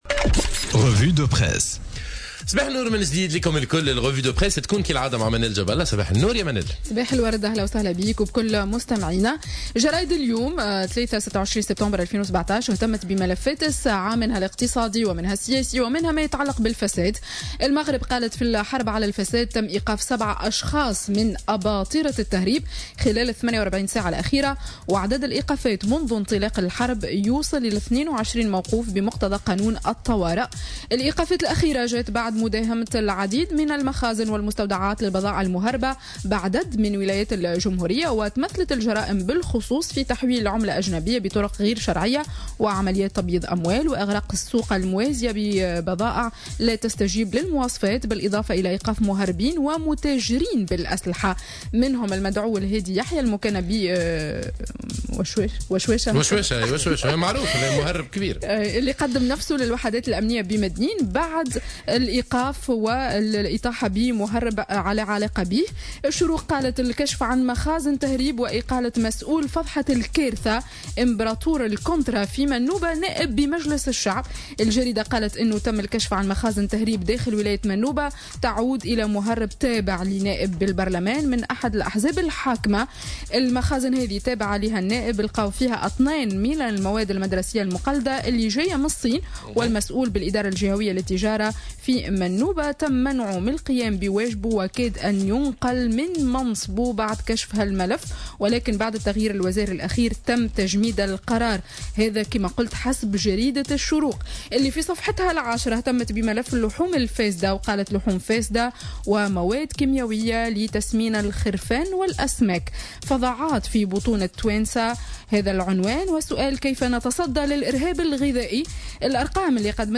Revue de presse du mardi 26 Septembre 2017